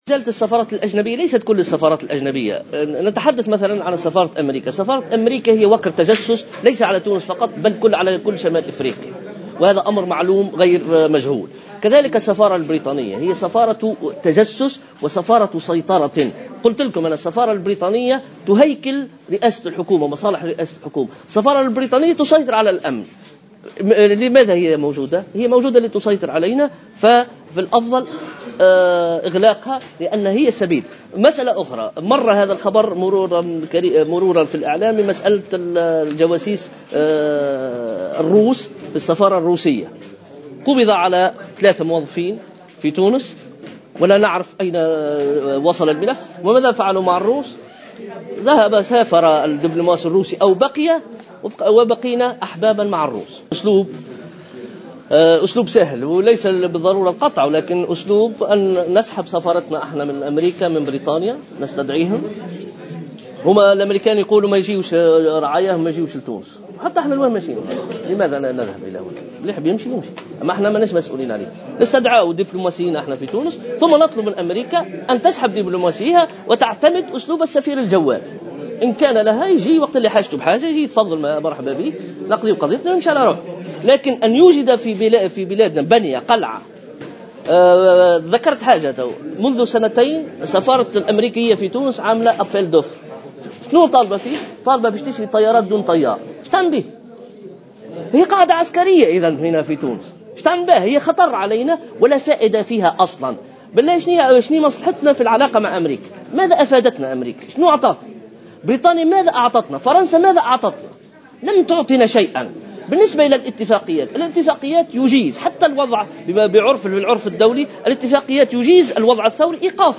خلال ندوة صحفية للحزب اليوم الجمعة